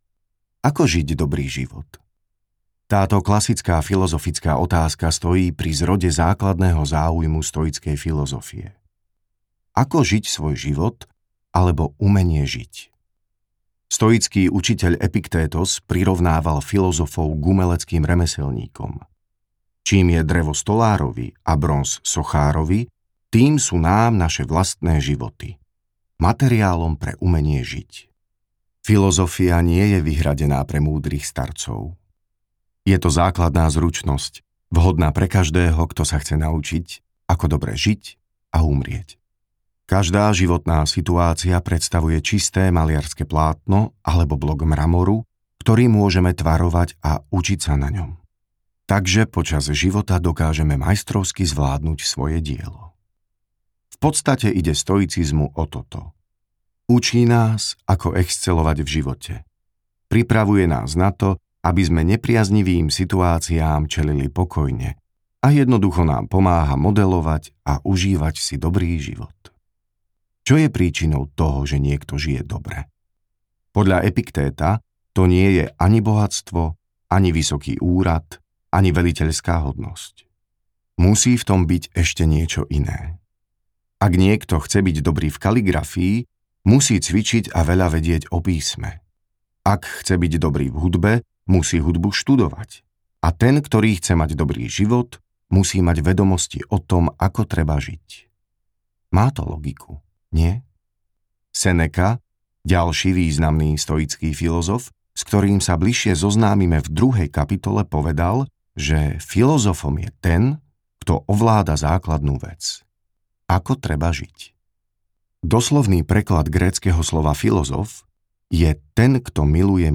Malá kniha stoicizmu audiokniha
Ukázka z knihy